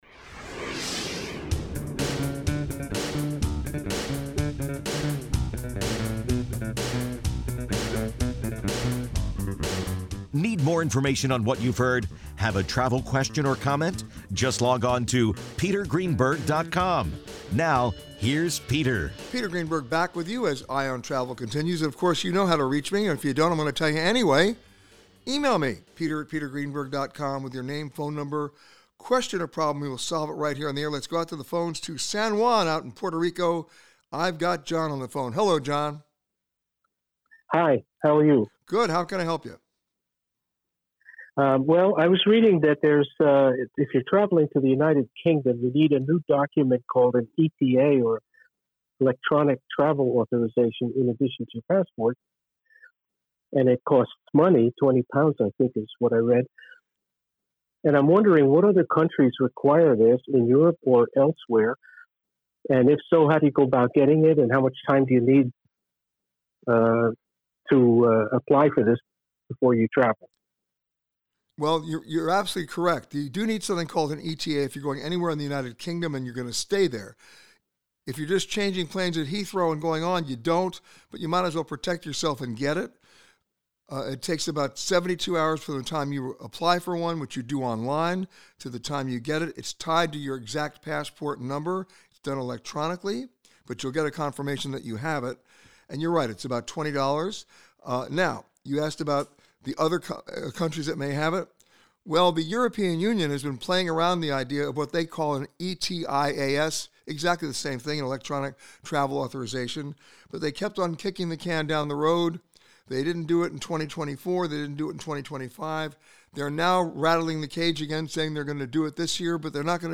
This week, Peter answered your questions from Malibu Beach Inn in Malibu, California. Peter answers your questions on visa requirements, when to pay for travel, visiting Mexico, and more.